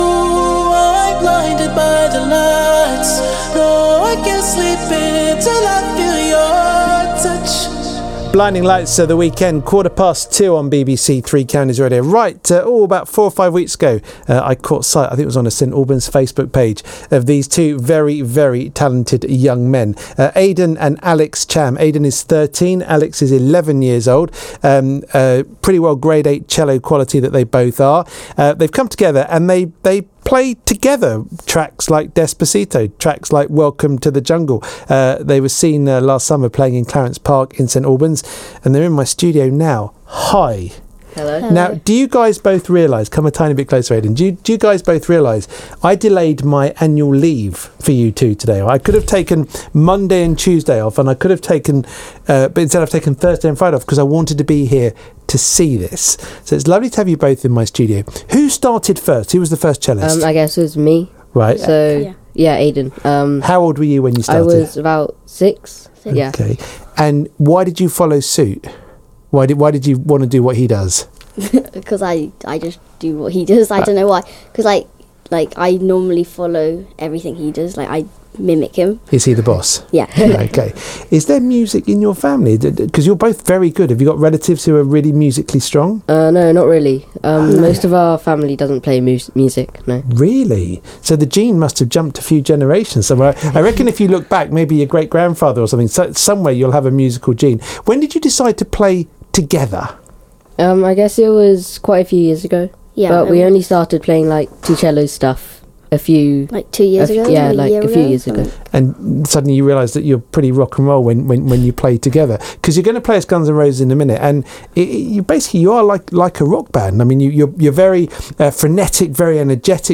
2Chams Perform Live on BBC Three Counties Radio!